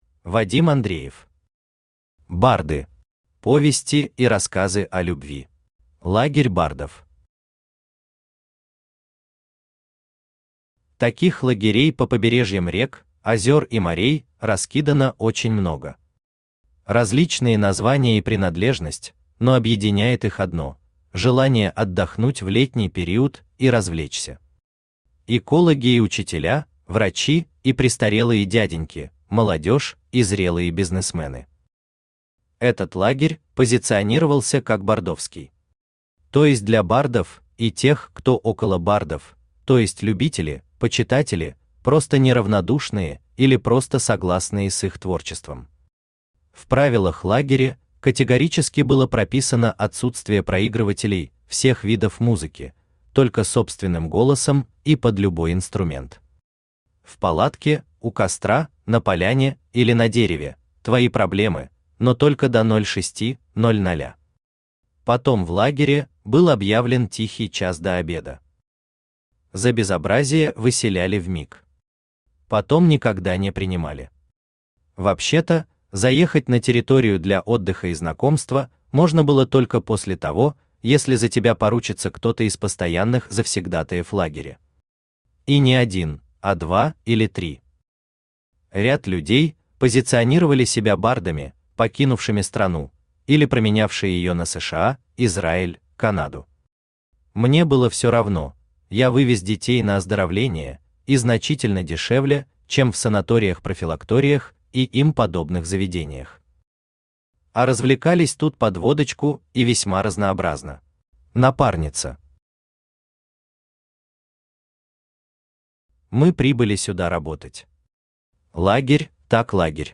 Аудиокнига Барды. Повести и рассказы о любви | Библиотека аудиокниг